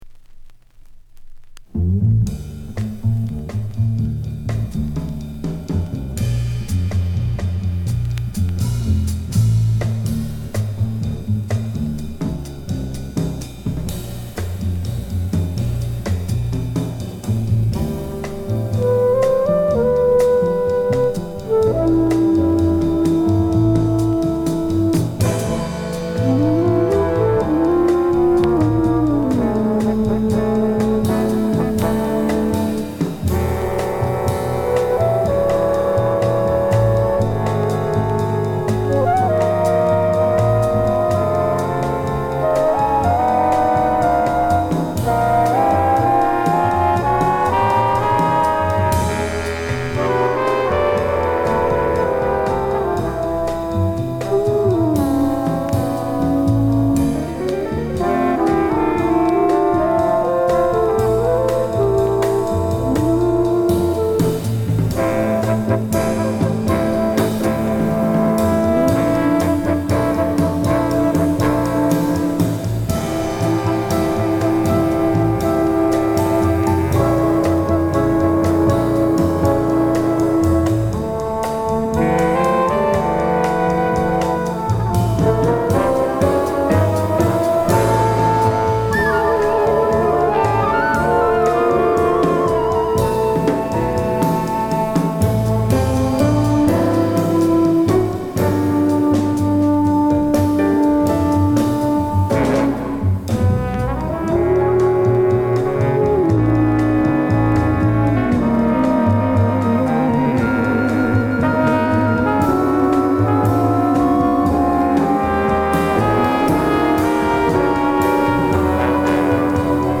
全く違うストレートなジャズを披露！